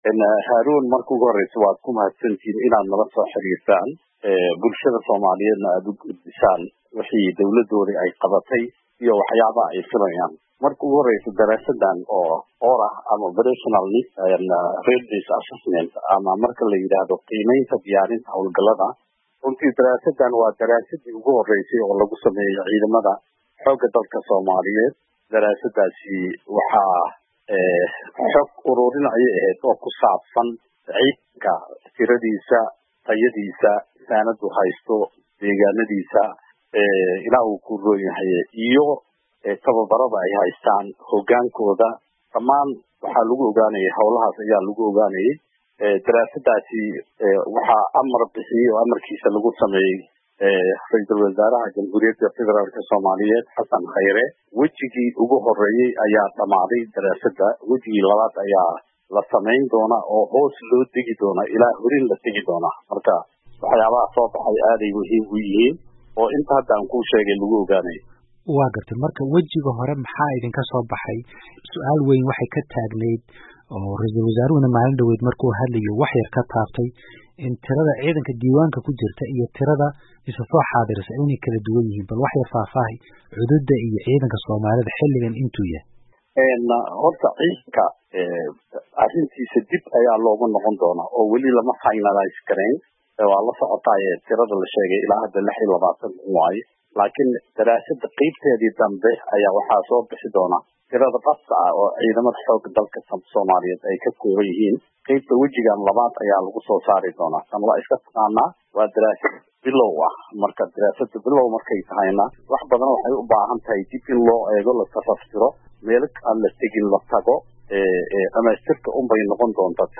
19 Dec 2017 (Puntlandes) Wasaaradda Gaashaandhigga ee DFS Maxamed Mursal Sheekh Cabdiraxmaan oo waraysi siiyey idaacadda VOA ayaa sheegtay in la dhammaystiray wejigii 1aad ee qiimaynta diyaarinta ciidanka Soomaalida.